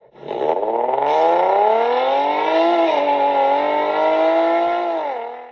motor.mp3